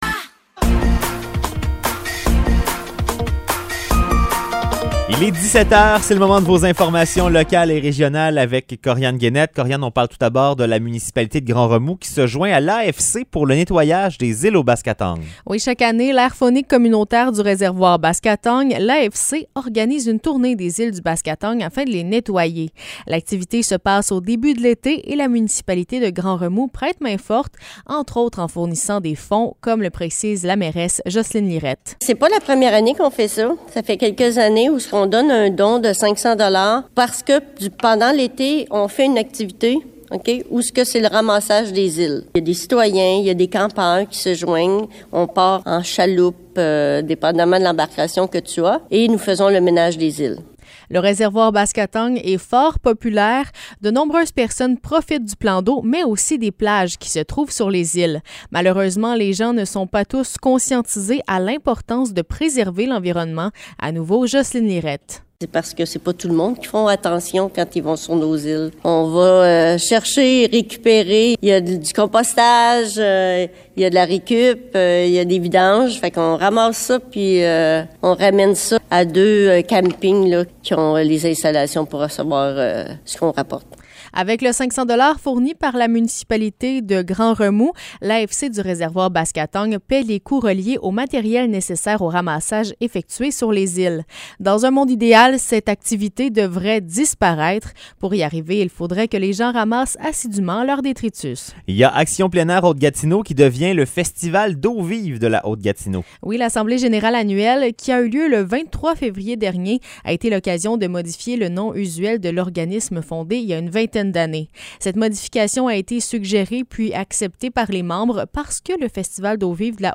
Nouvelles locales - 9 mars 2023 - 17 h